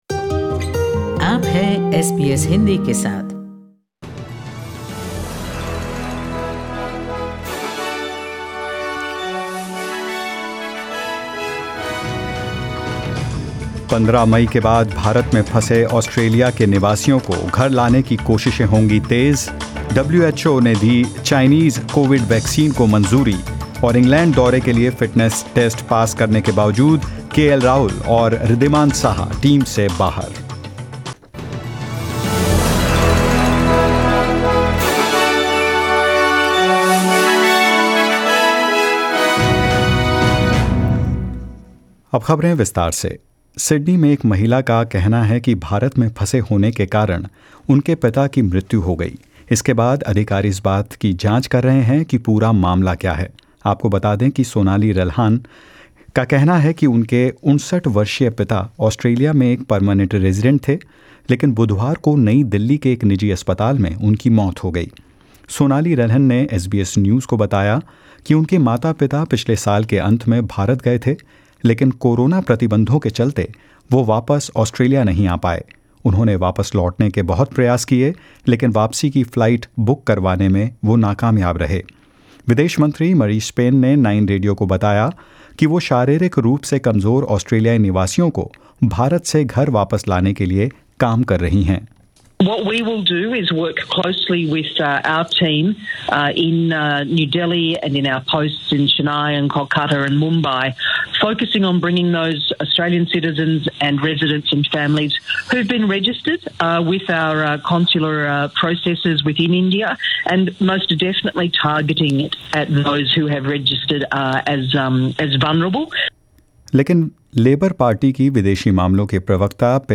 In this latest SBS Hindi News bulletin of India and Australia: The World Health Organisation approves a Chinese COVID vaccine for worldwide use; Sydney residents are urged to wear masks and follow restrictions ahead of Mothers Day and much more. 8/05/21